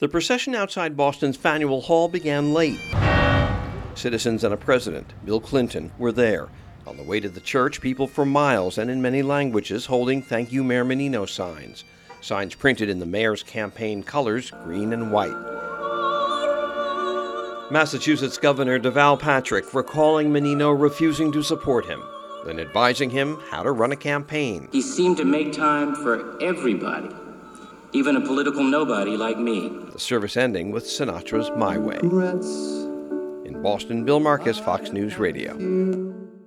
(BOSTON) NOV 3 – THE FUNERAL OF FORMER LONG-TIME BOSTON MAYOR THOMAS MENINO WAS CELEBRATED TODAY IN A MASS IN THE NEIGHBORHOOD CHURCH WHERE HE WAS BAPTIZED.